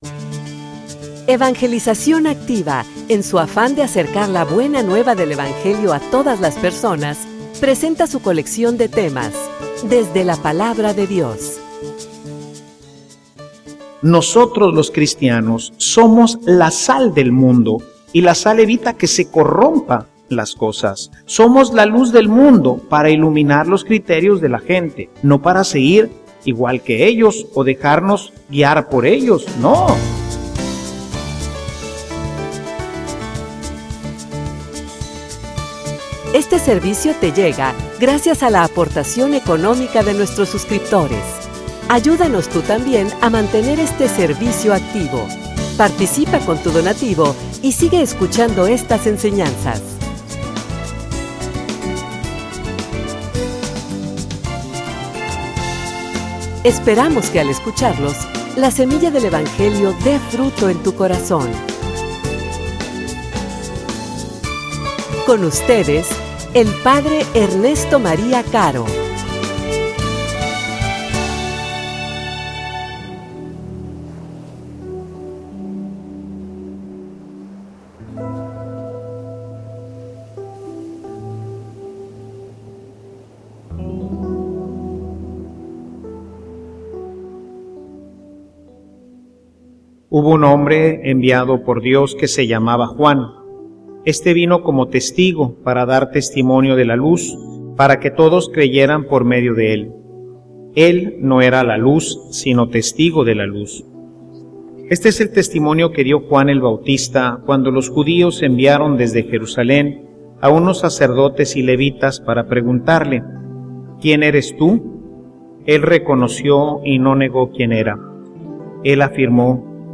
homilia_Encontrar_al_que_esta_entre_nosotros.mp3